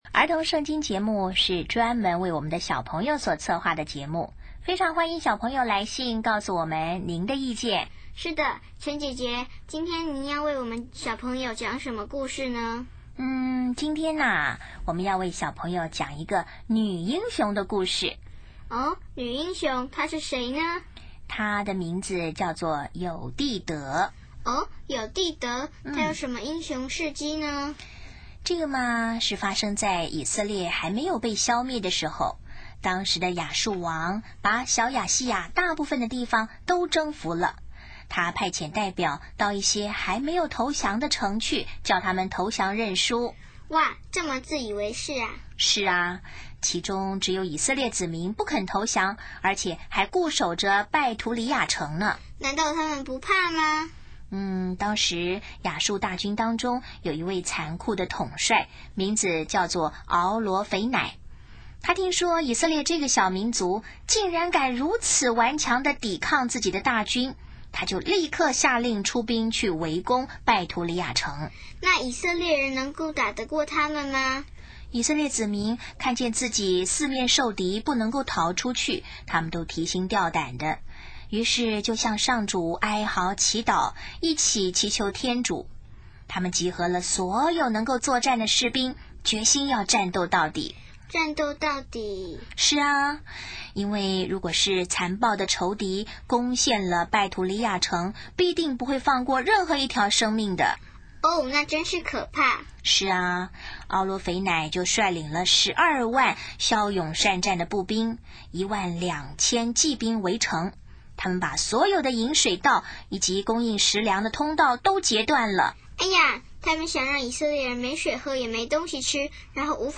【儿童圣经故事】38|友弟德传：以色列的女英雄